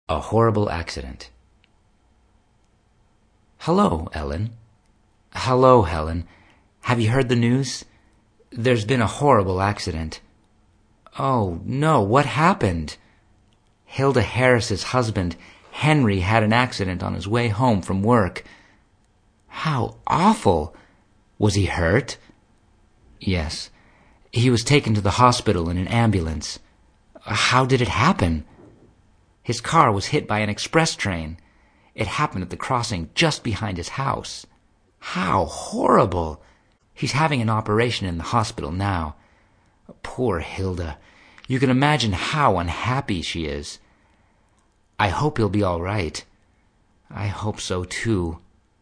Hi Classes – below are recordings of myself reading the dialogs you have chosen. Listen to the examples and practice with the stress I use.